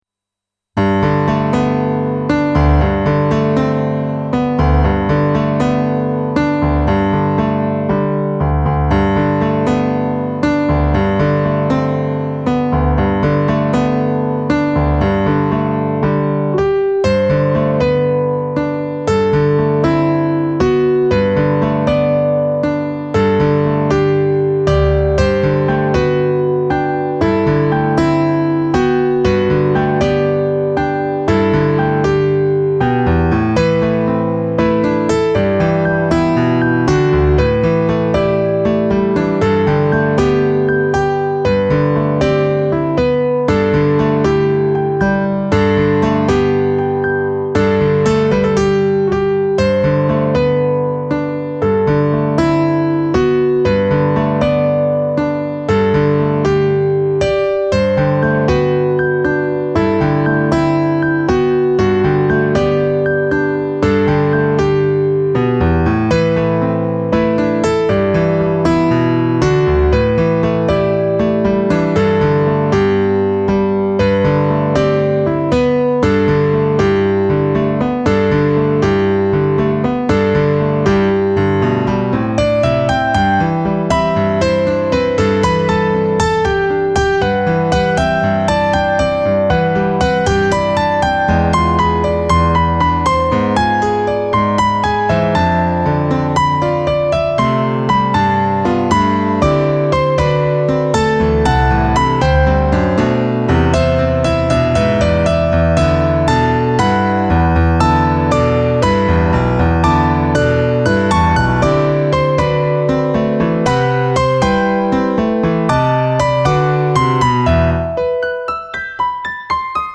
音源は、ＳＣ８８２０を購入したので、さらに良くなっているはず。
ピアノソロ。
クラシックな感じをもっと出したかったけど、なぜかかなり「和」の雰囲気が。